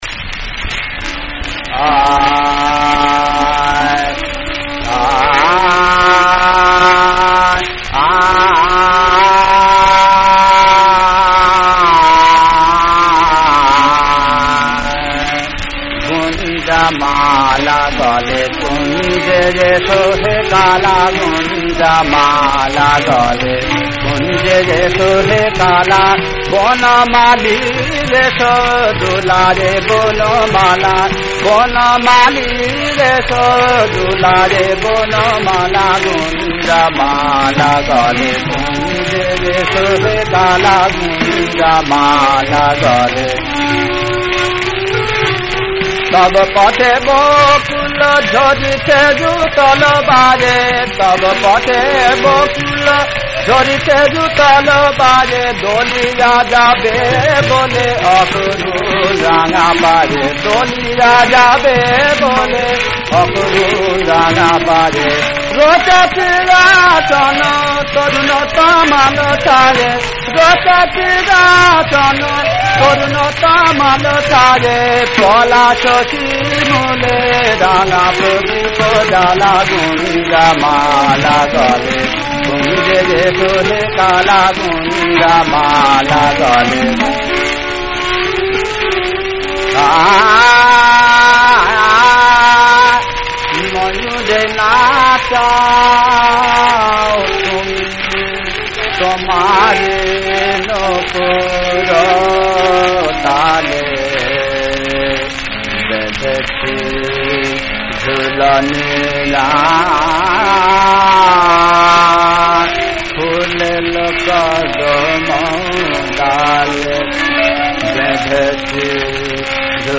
রাগ: মালগুঞ্জ, তাল: ত্রিতাল।
এটি একটি হিন্দি খেয়াল ভাঙা গান